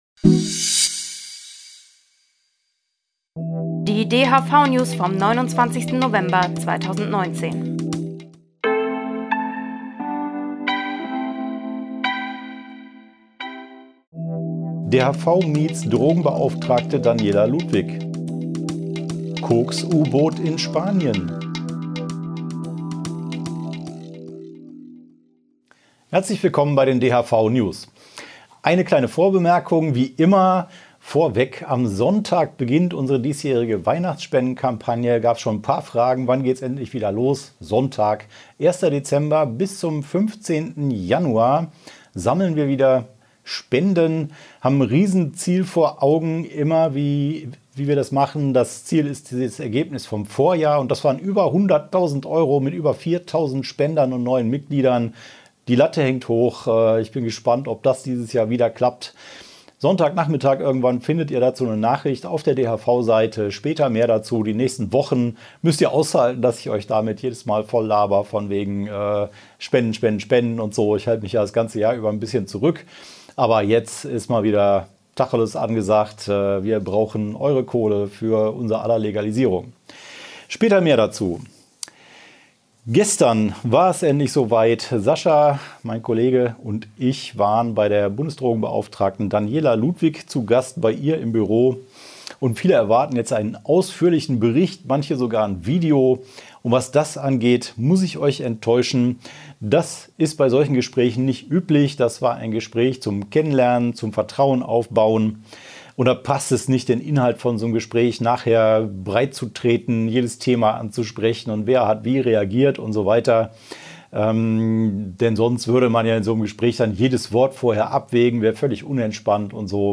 DHV-Video-News #228 Die Hanfverband-Videonews vom 29.11.2019 Die Tonspur der Sendung steht als Audio-Podcast am Ende dieser Nachricht zum downloaden oder direkt hören zur Verfügung.